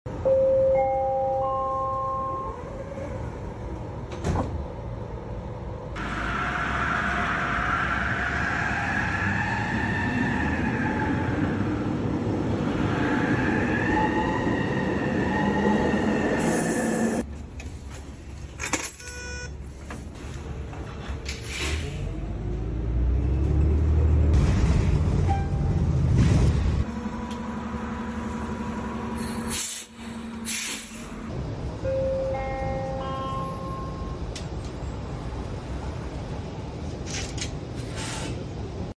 Sounds of TransLink